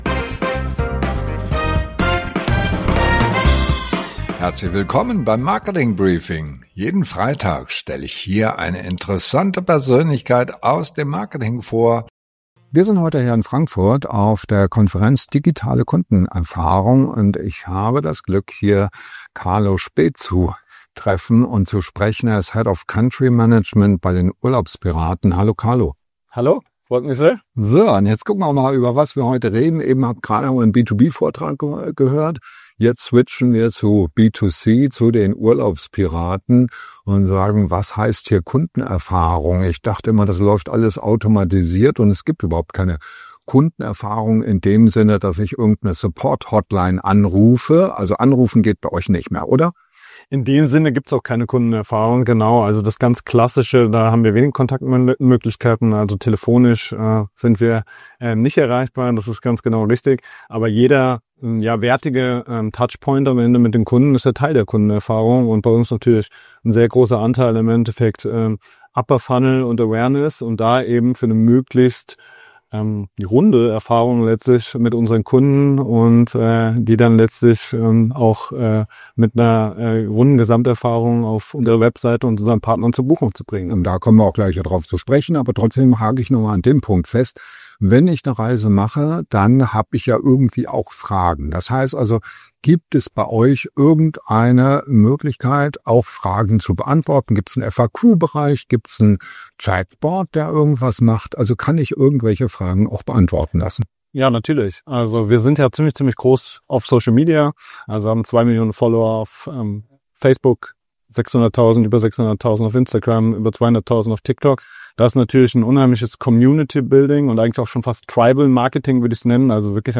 Jeden Freitag Interviews mit spannenden Persönlichkeiten aus der Digital- & Marketing-Szene